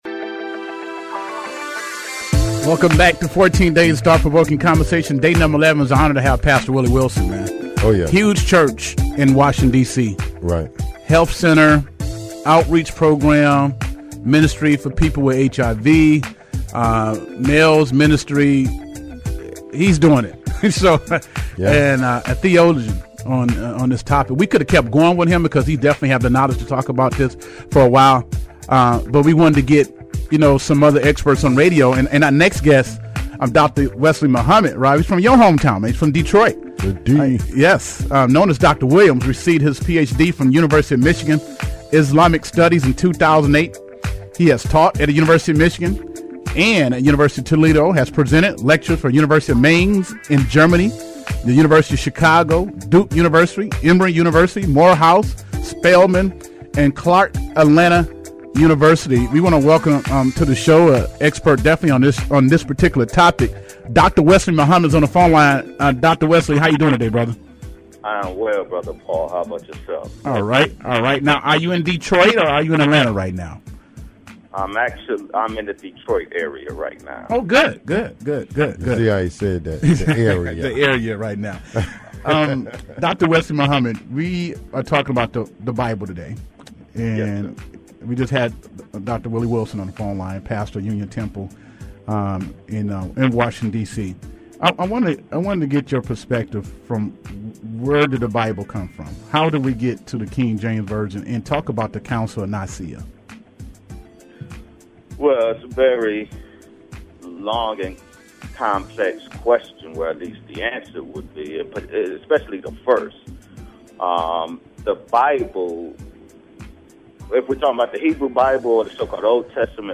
In depth interview